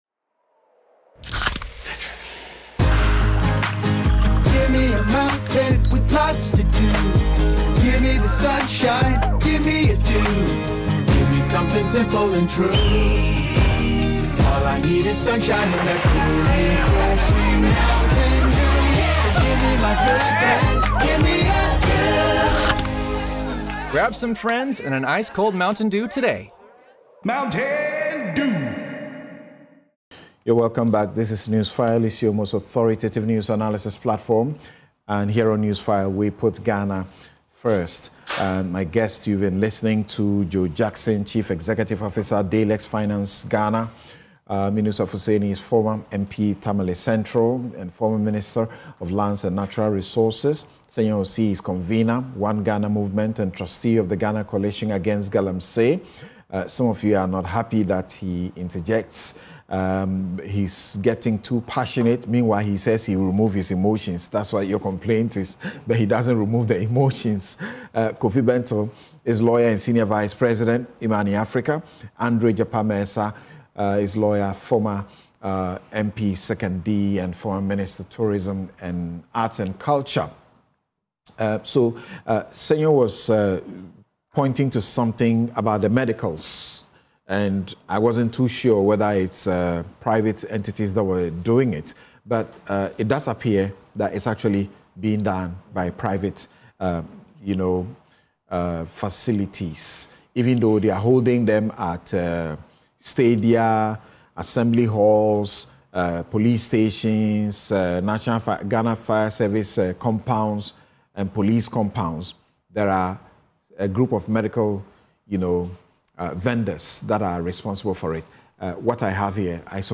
Analysis and discussion of news and current affairs in Ghana with panellists